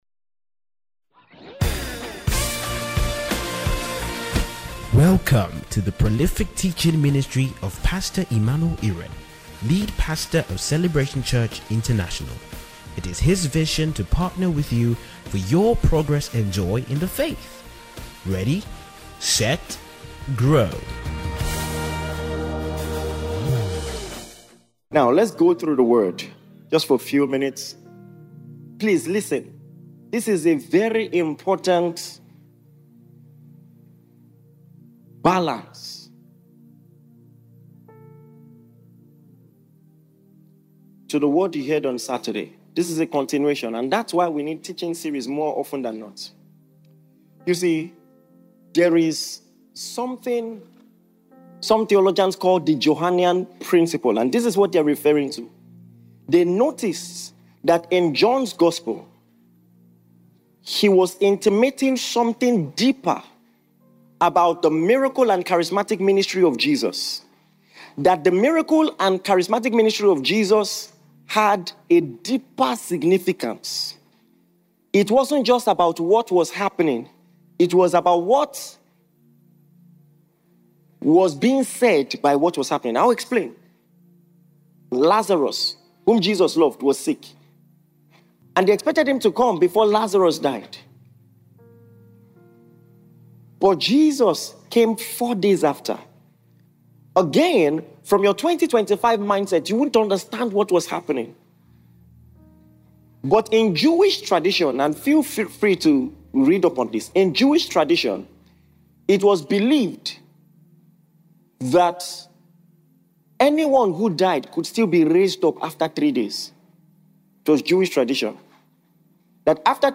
Welcome to the official Celebration Church International sermons feed, where you’ll find the latest sermons and teachings from Celebration Church International.
thanksgiving-service.mp3